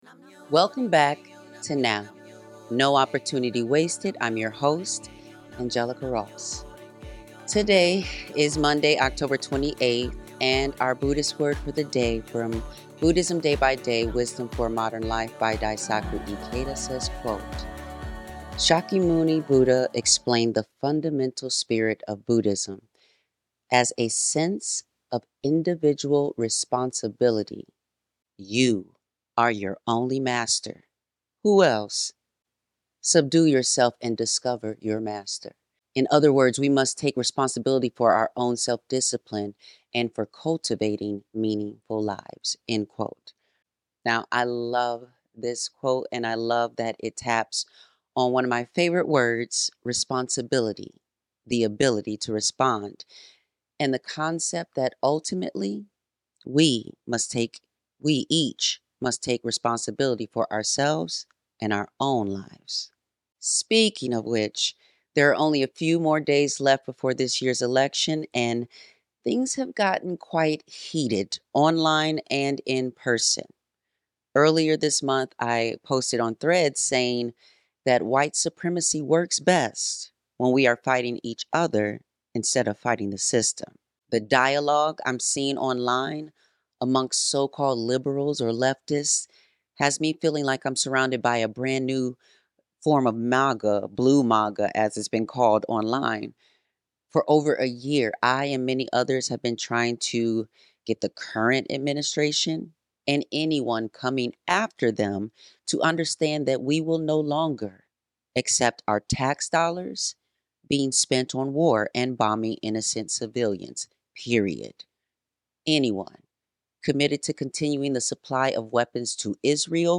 Karaoke Queen: A Conversation